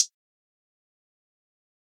Hi-Hat [Metro].wav